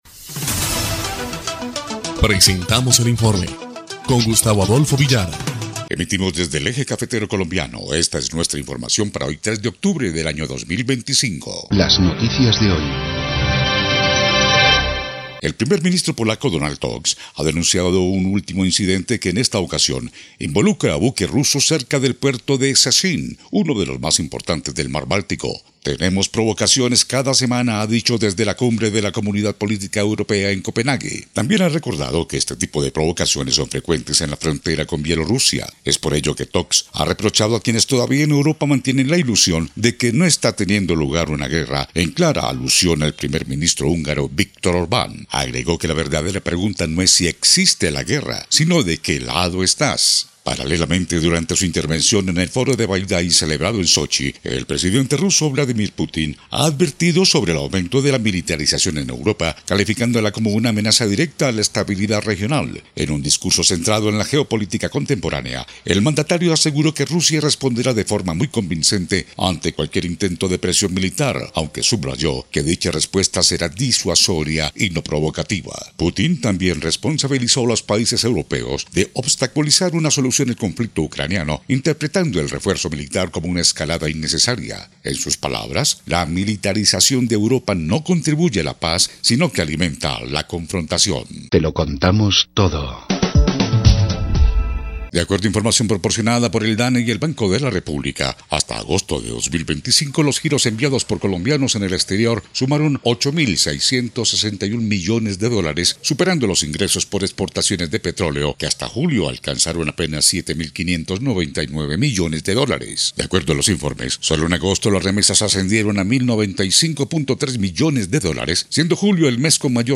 EL INFORME 1° Clip de Noticias del 3 de octubre de 2025